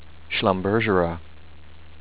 schlum-BER-ger-uh